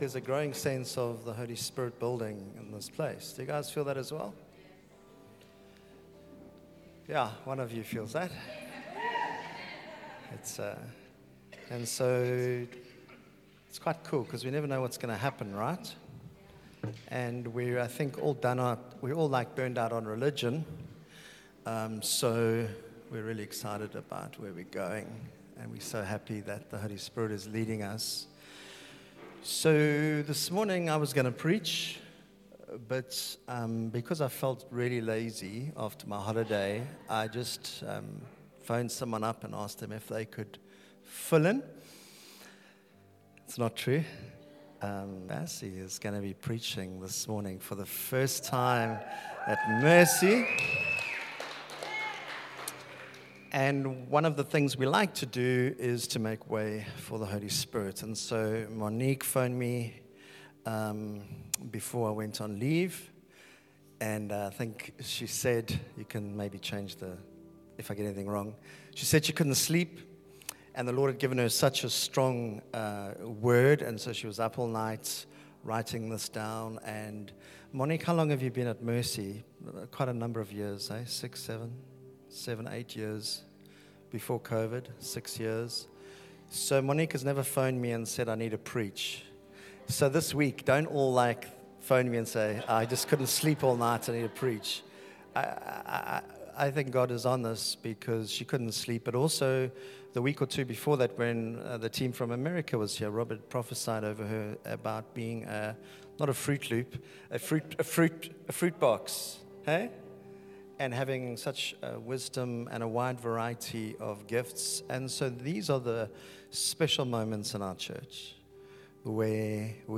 Sunday Service – 26 October
Sermons